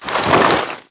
gear_rustle
tac_gear_4.ogg